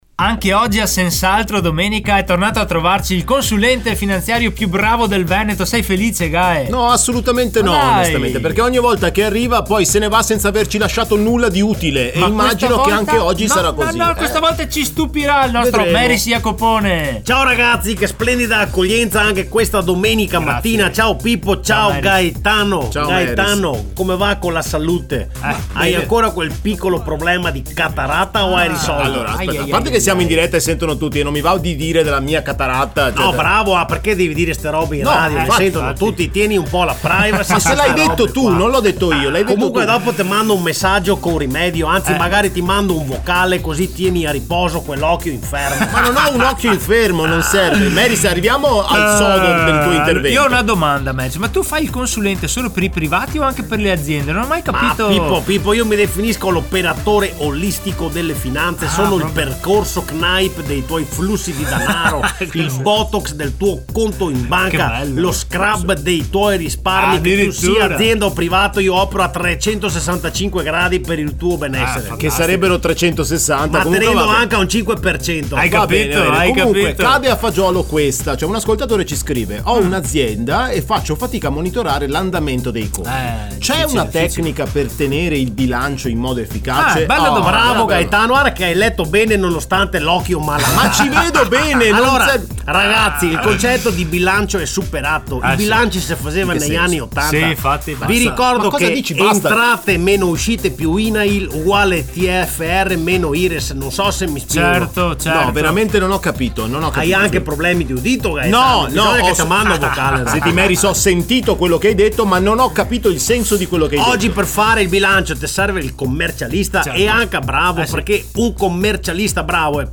🎙 Ogni settimana, uno sketch ironico e fuori dagli schemi ha raccontato – a modo nostro – quanto può fare la differenza affidarsi a chi lavora con PROFIS.
Tra gag, colpi di scena e un consulente un po’... così, il risultato è tutto da ascoltare!